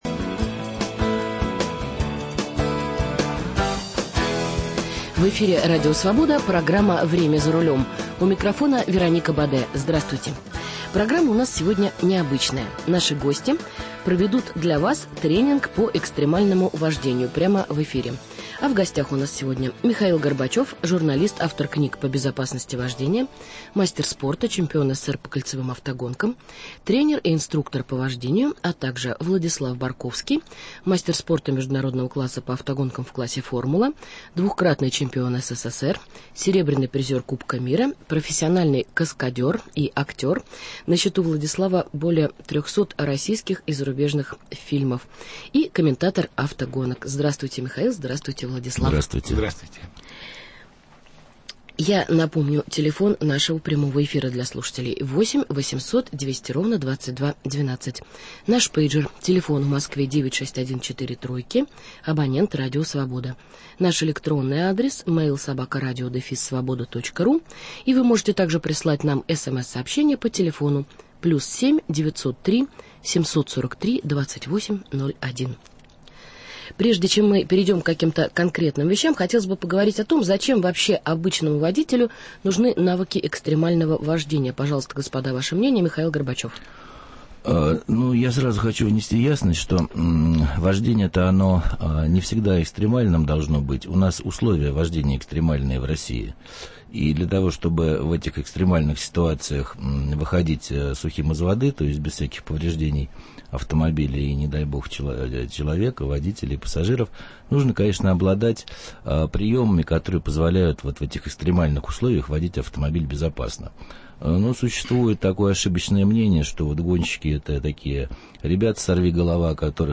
Уроки экстремального вождения в прямом эфире